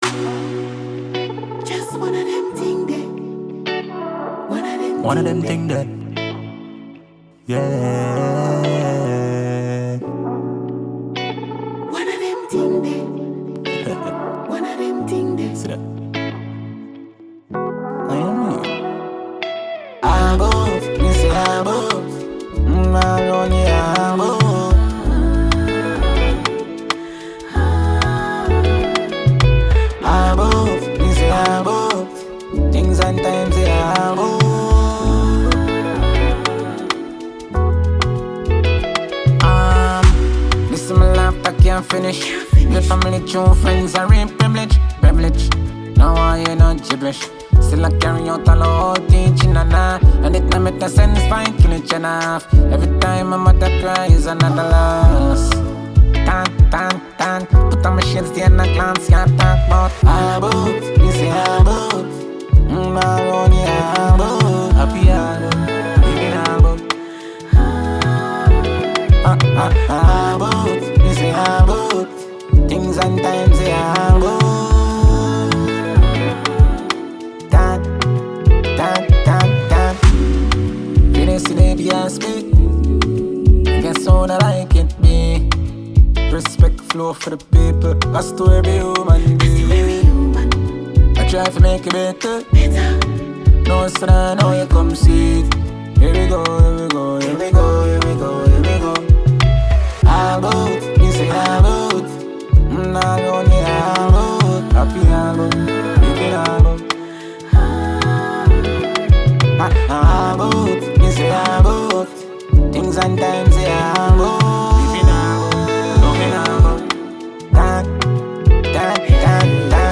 Dancehall/HiphopMusic
Jamaican award winning dancehall act